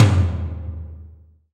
TOM TOM230QL.wav